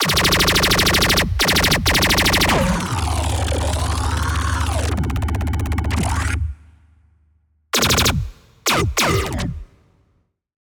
Laser Gun 2
Laser-Gun-02-Example.mp3